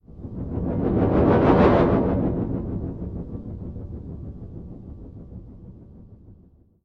На этой странице собраны звуки бумеранга: от свиста в полете до мягкого приземления в руку.
Звук летящего бумеранга для видеомонтажа